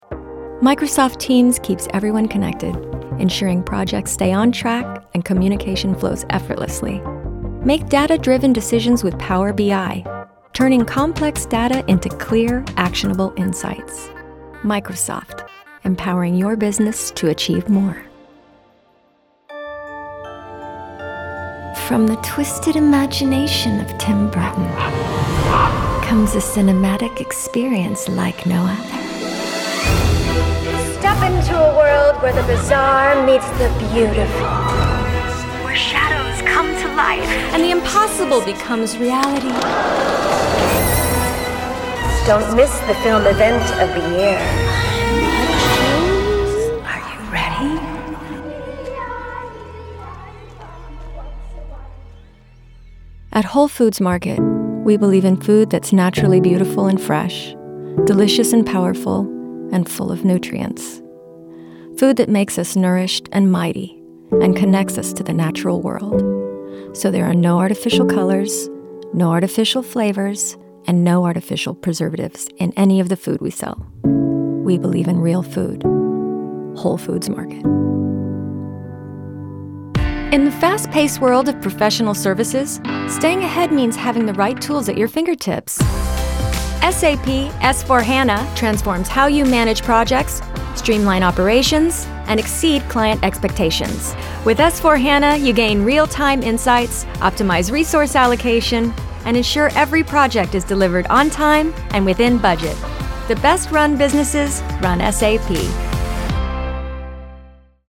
Female
40s/50s, 50+